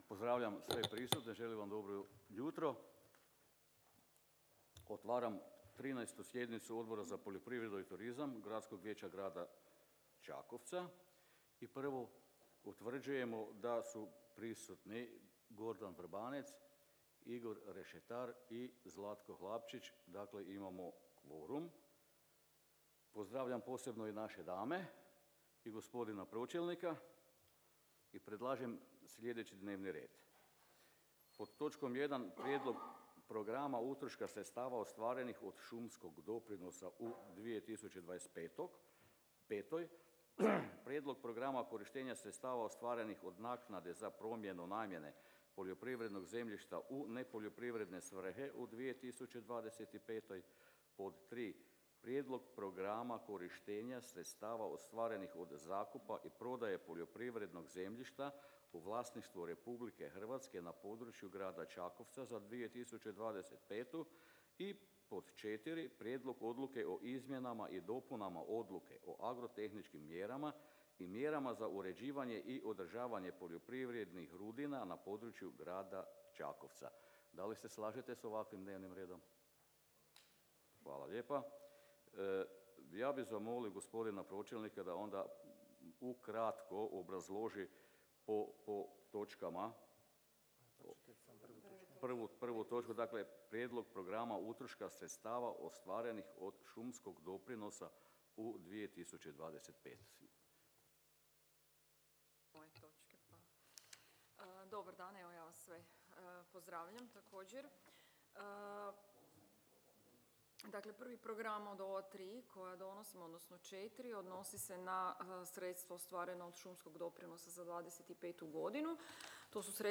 Obavještavam Vas da će se 13. sjednica Odbora za poljoprivredu i turizam Gradskog vijeća Grada Čakovca održati 6. prosinca 2024. (petak) u 08:30 sati, u gradskoj vijećnici Grada Čakovca, Ulica kralja Tomislava 15, Čakovec.